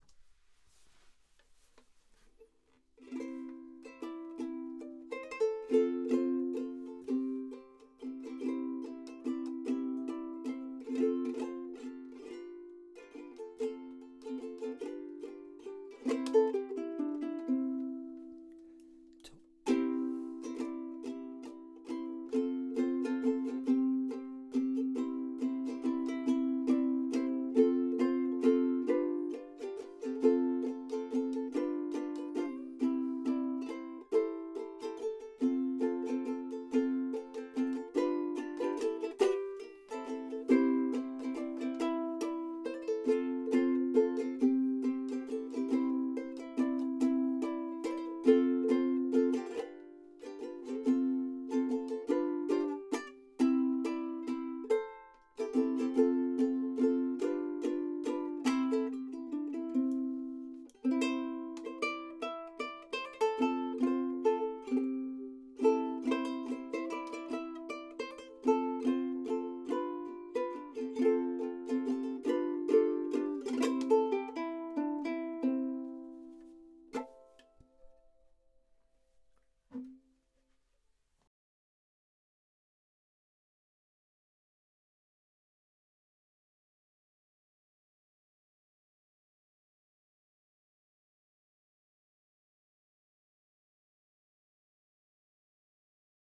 Toto mein Toto zum Mitsingen DOWNLOAD